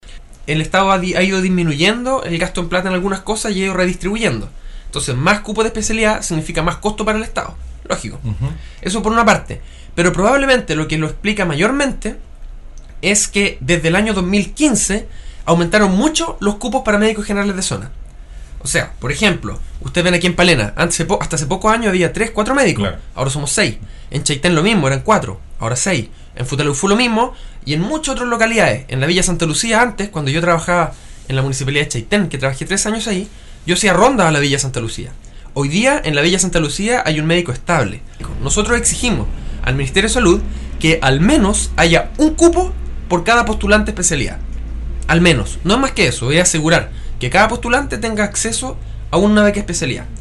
Señaló en entrevista con radio Estrella del Mar de Palena que la denominada estrategia de médicos generales de zona tiene un muy positivo impacto en atención de las poblaciones más aisladas y remotas del territorio nacional.